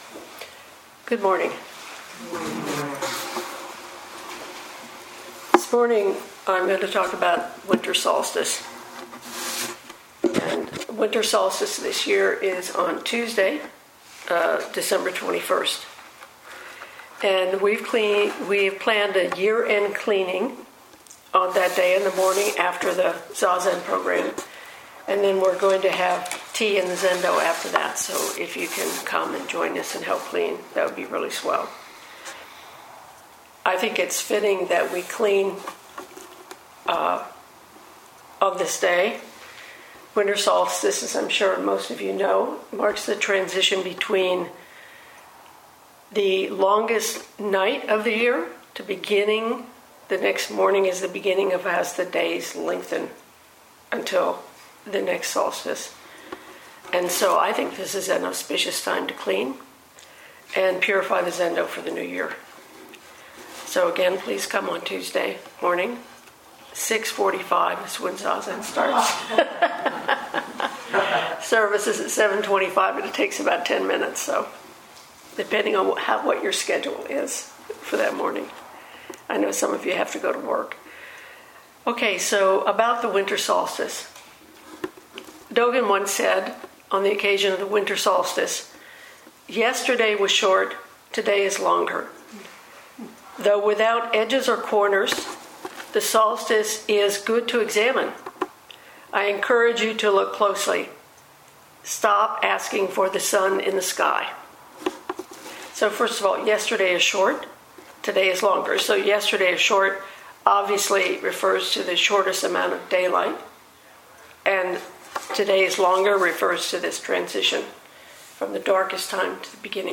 2021 in Dharma Talks